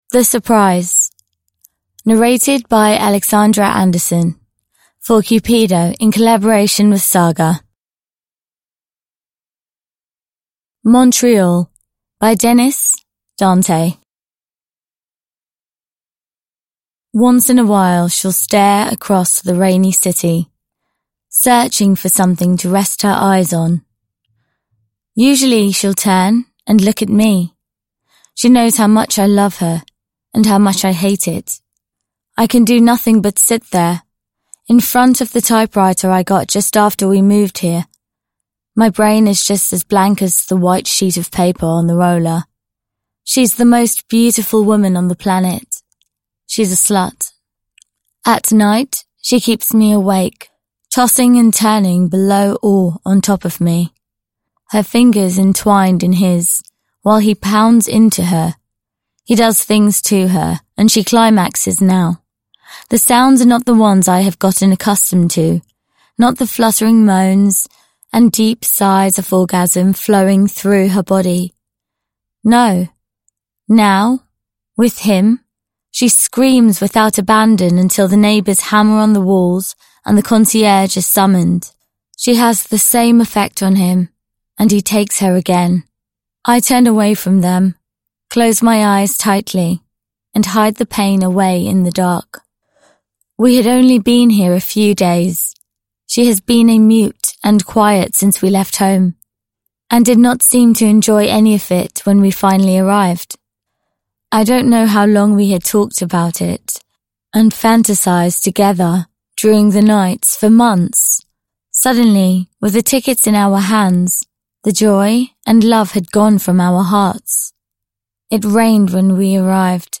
The surprise (ljudbok) av Cupido